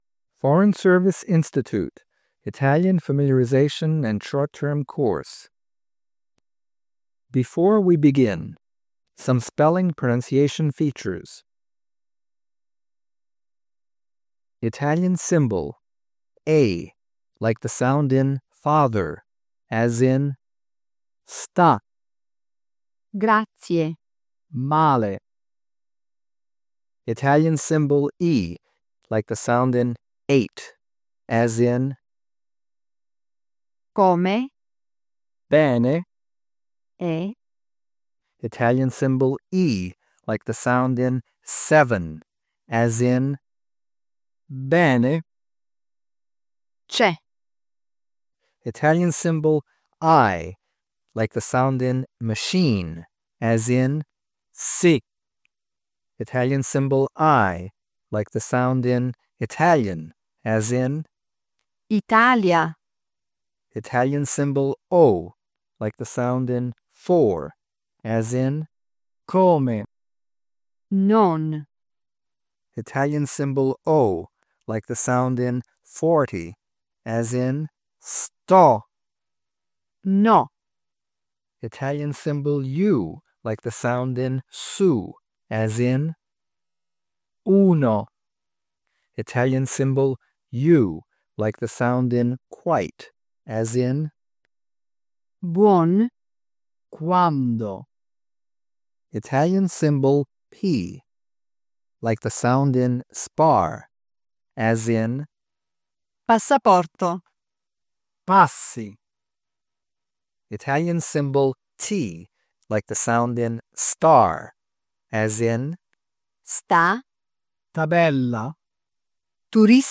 (Side note: Yeesh, I forgot how low-quality 90s audio recordings were… thankfully, they sound better after some AI processingoriginal vs